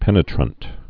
(pĕnĭ-trənt)